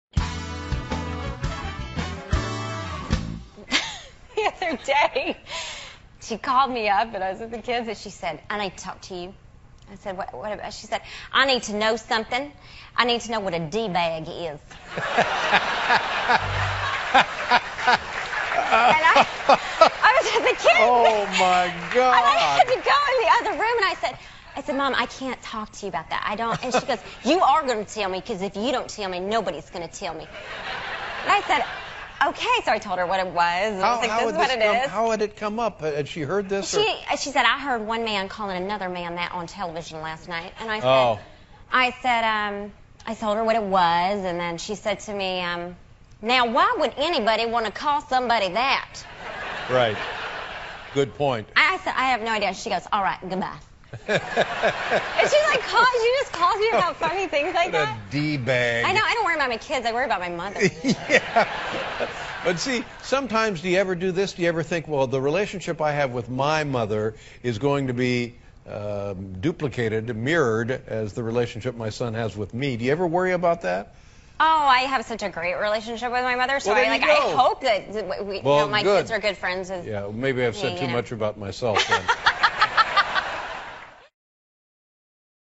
访谈录 2012-02-15&02-17 甜心瑞茜·威瑟斯彭与妈妈的趣事 听力文件下载—在线英语听力室